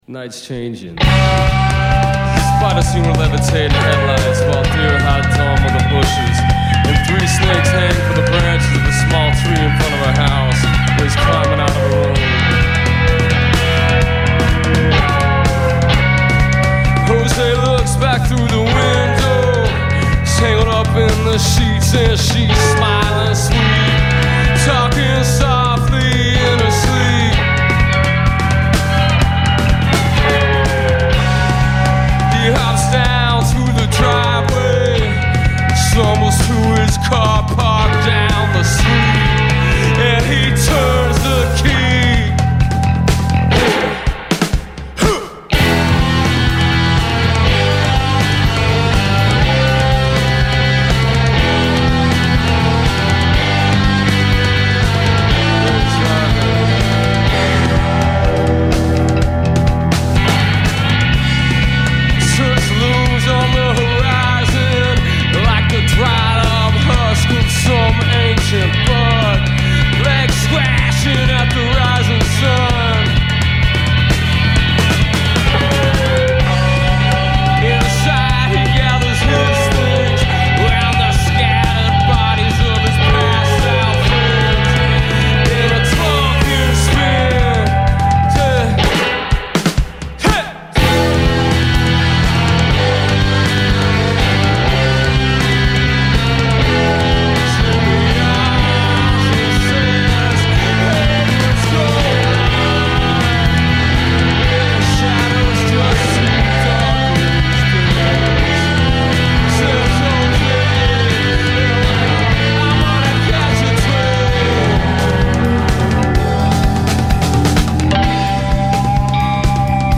lead vocals, guitar
drums
bass